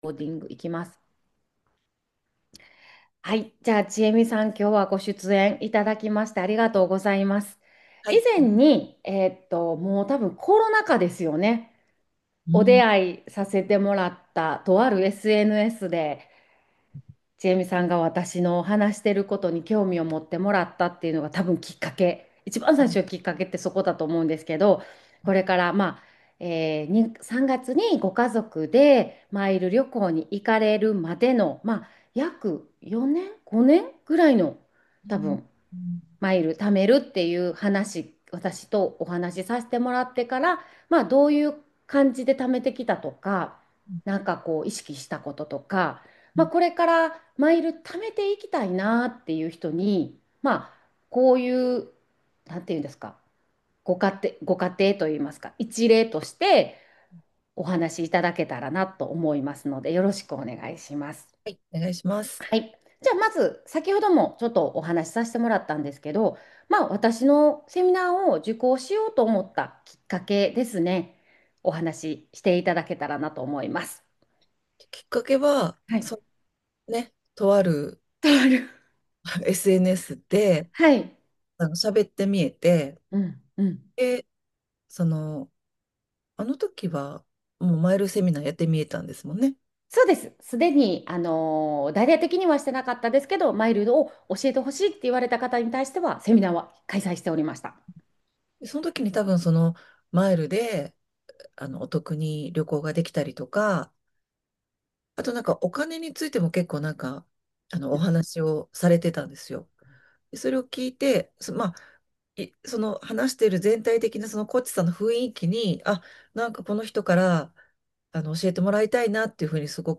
※マイルで25万円お得に台湾旅行へ行かれたセミナー受講者さまとの対談音声